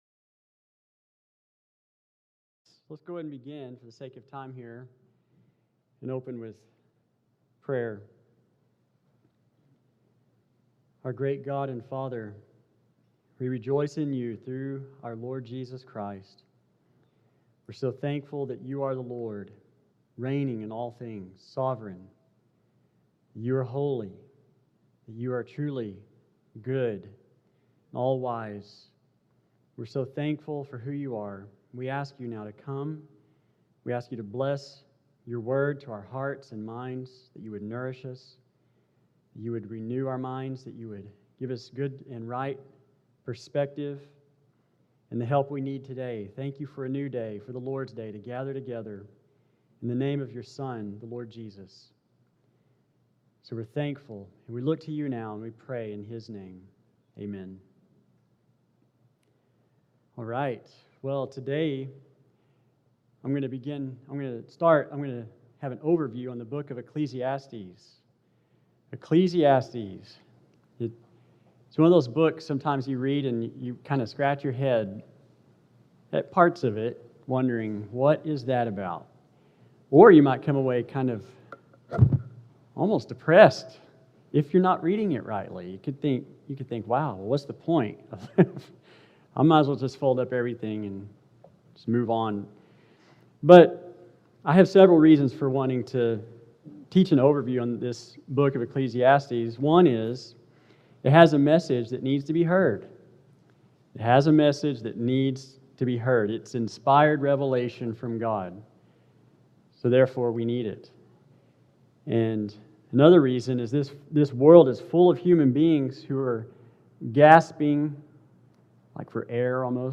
An Overview of Ecclesiastes | SermonAudio Broadcaster is Live View the Live Stream Share this sermon Disabled by adblocker Copy URL Copied!